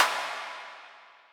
Index of /musicradar/layering-samples/Drum_Bits/Verb_Tails